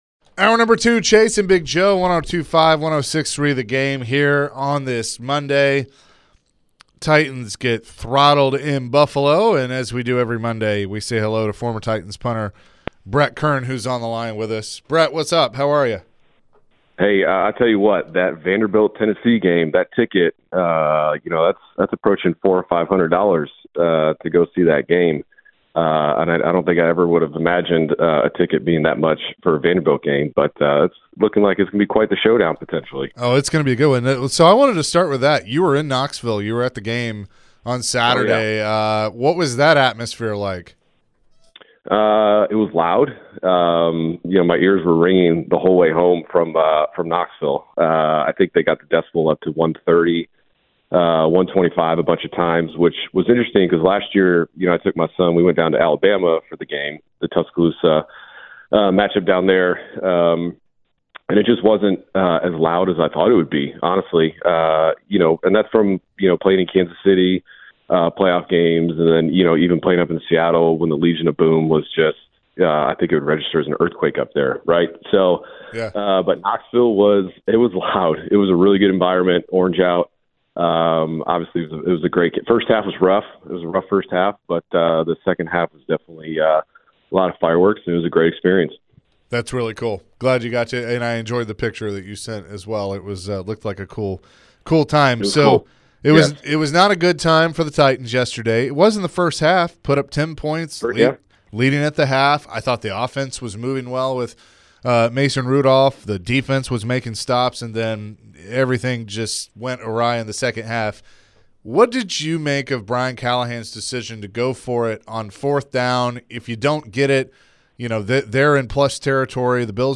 Former Titans Punter Brett Kern joined the show and talked about the different situations that led to the Titans losing to the Bills yesterday and what they could have done better throughout the game.